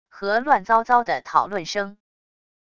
和乱糟糟的讨论声wav音频